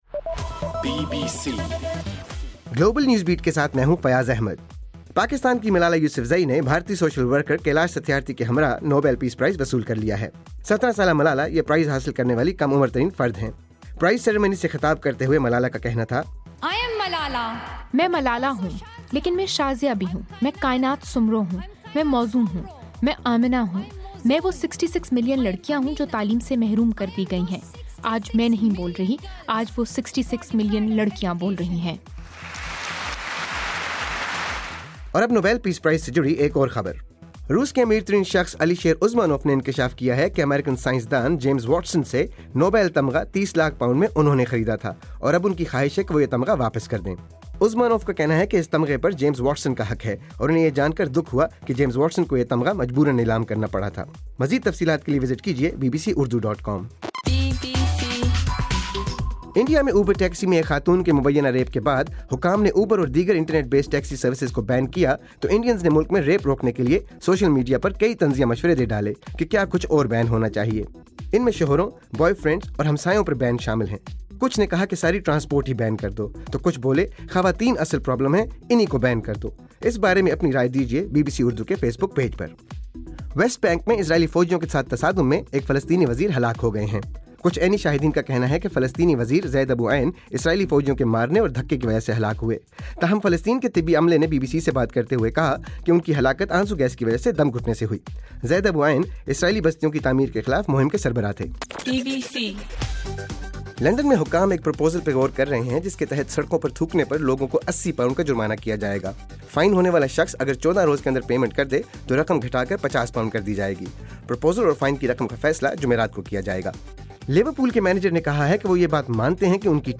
دسمبر 10: رات 10 بجے کا گلوبل نیوز بیٹ بُلیٹن